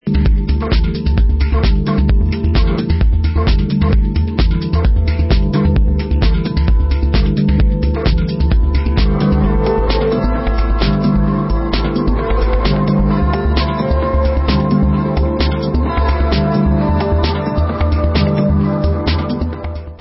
sledovat novinky v oddělení Dance/Techno